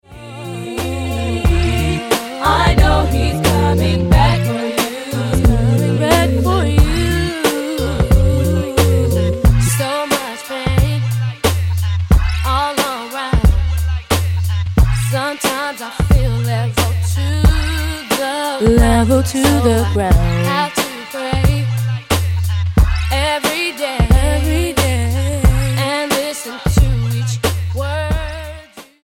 R&B gospel
Style: R&B